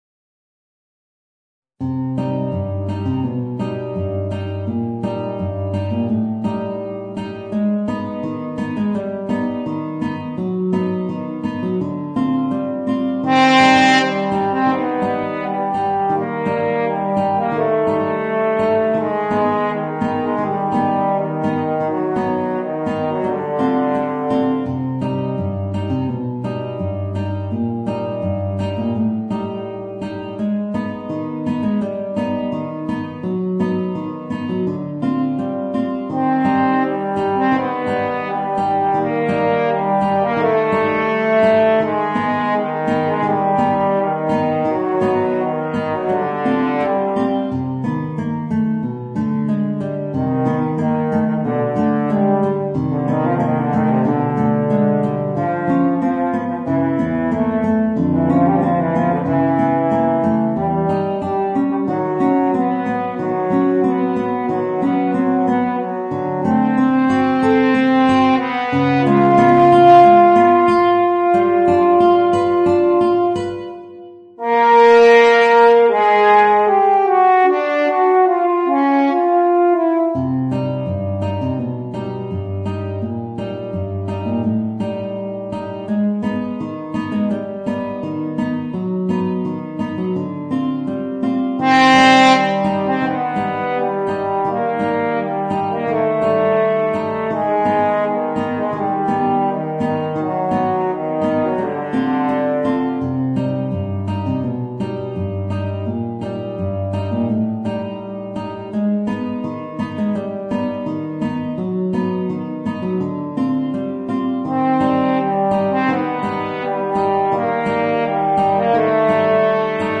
Voicing: Guitar and Horn